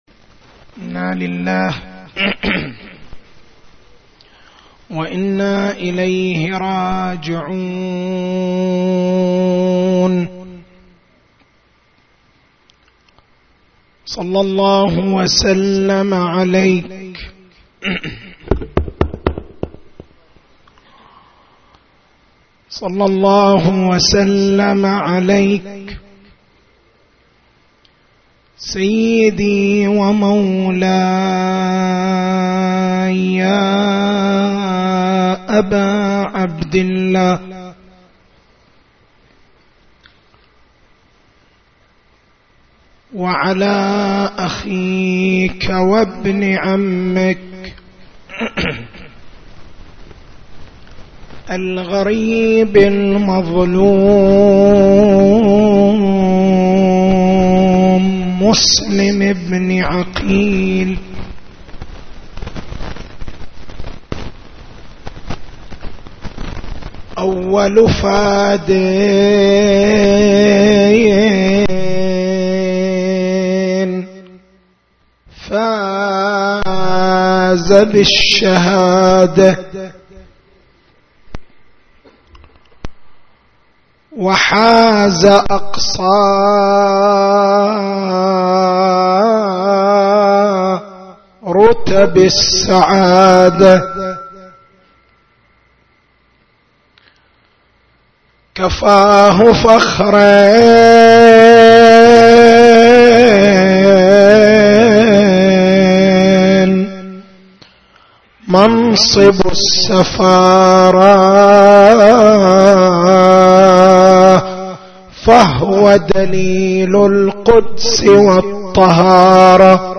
تاريخ المحاضرة: 04/01/1434 محور البحث: هل يجب الإصغاء لدعاوى المهدويّة دفعًا للضرر المحتمل؟